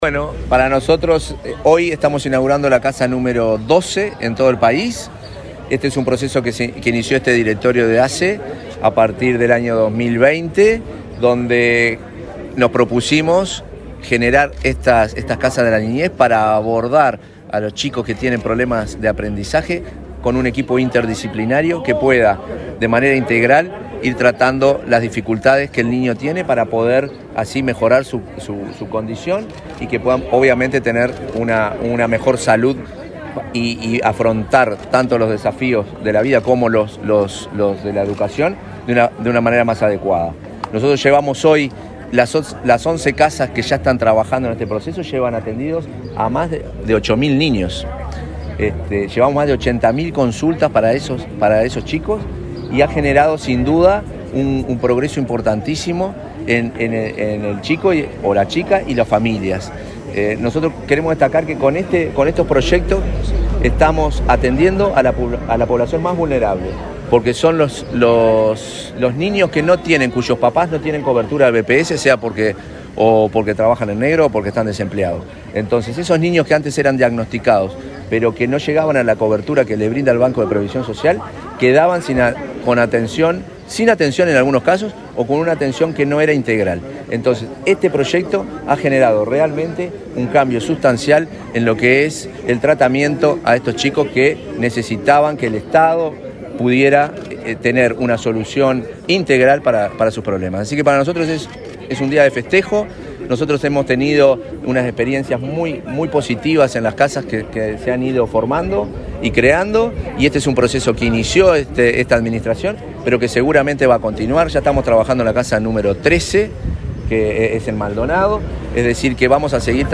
Declaraciones del presidente de ASSE, Marcelo Sosa
Declaraciones del presidente de ASSE, Marcelo Sosa 12/11/2024 Compartir Facebook X Copiar enlace WhatsApp LinkedIn Este martes 12, el presidente de la Administración de los Servicios de Salud del Estado (ASSE), Marcelo Sosa, encabezó la inauguración de una casa de desarrollo de la niñez en Durazno. Antes, dialogó con la prensa.